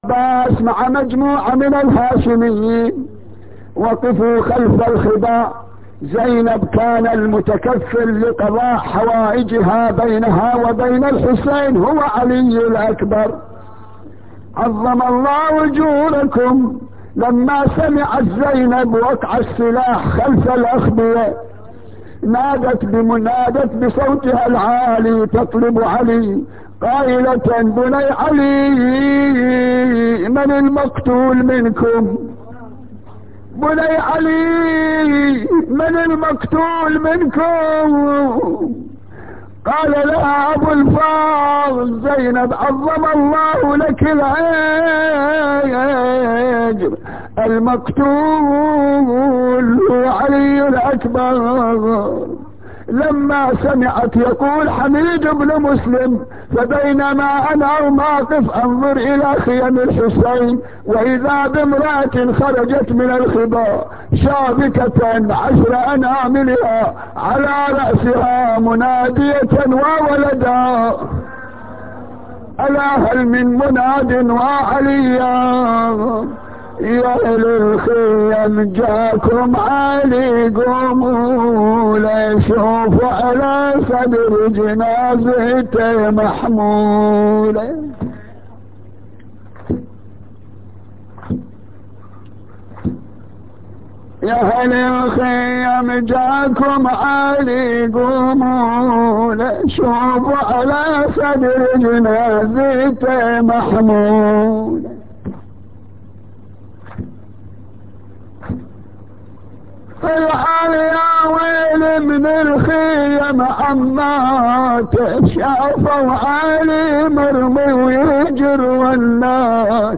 نواعي حسينية 5